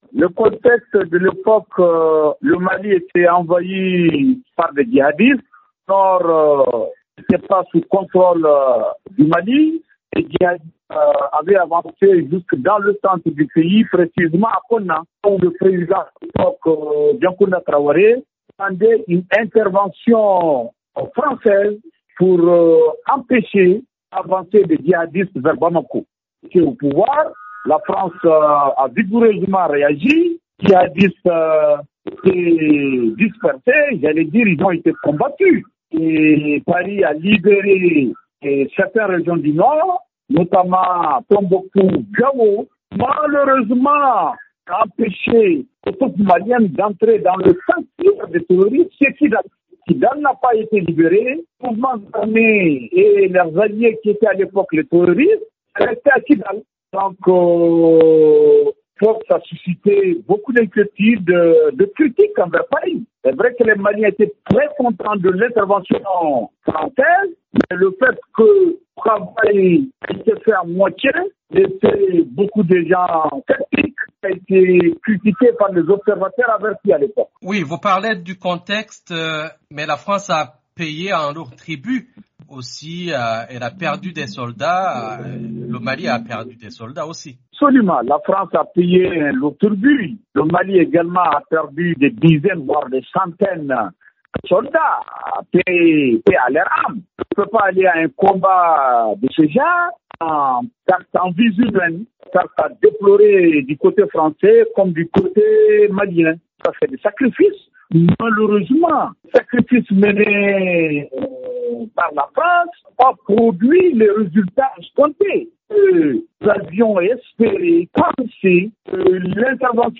a joint à Bamako, le journaliste